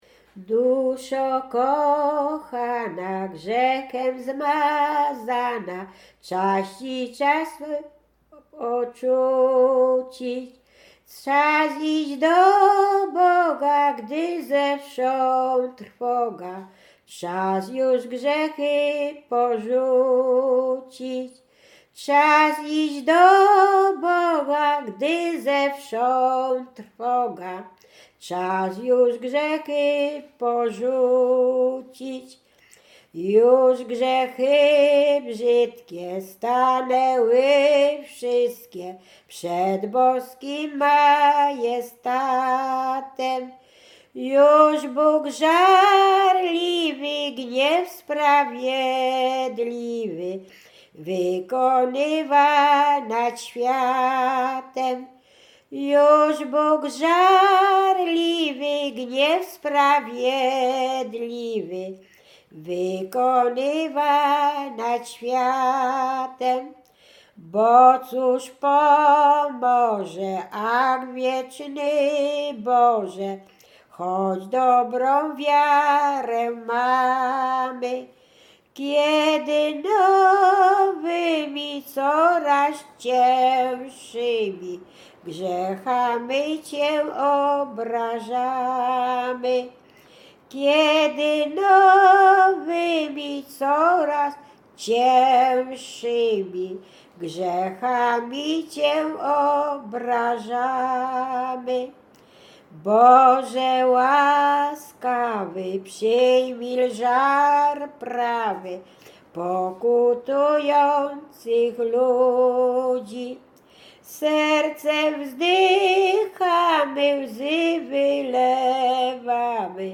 Łęczyckie
Pogrzebowa
pogrzebowe nabożne katolickie do grobu